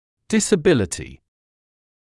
[ˌdɪsə’bɪlətɪ][ˌдисэ’билэти]физическая или умственная неспособность использовать какую-то часть тела или усваивать знания